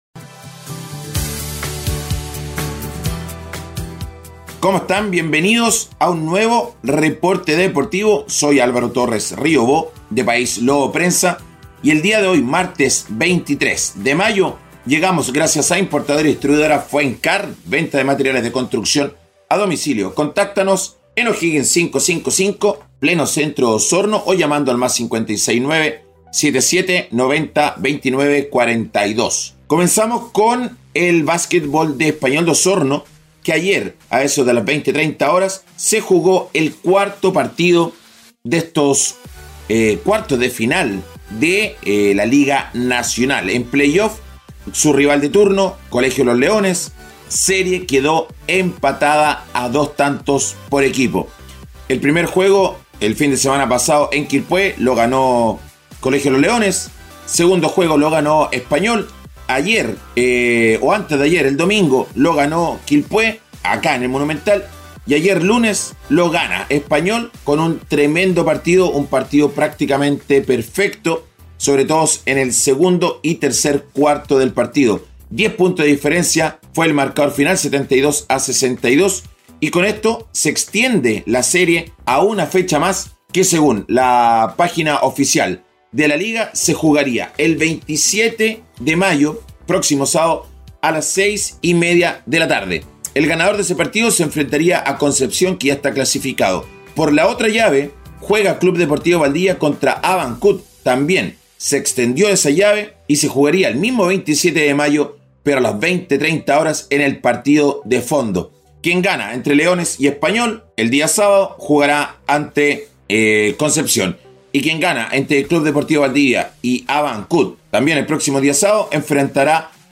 un breve reporte